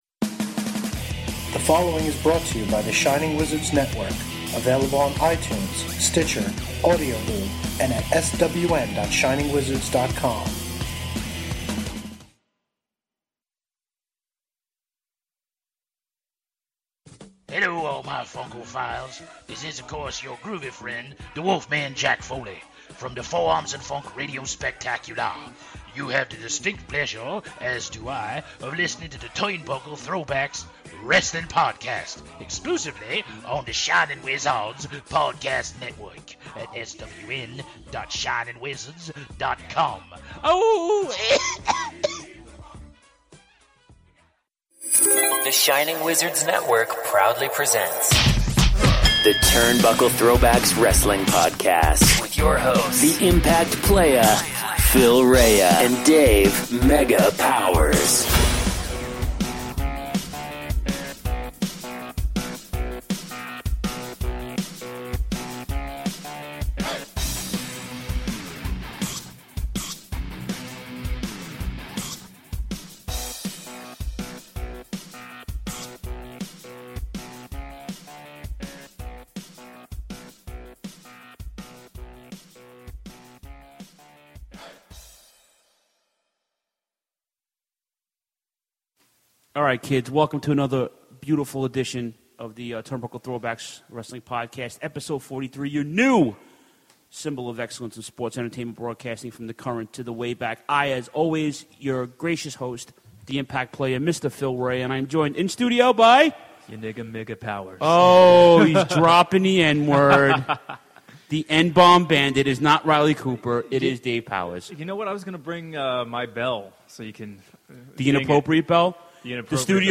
Mega Powers is back in the studio as the boys cover the 1990 Survivor Series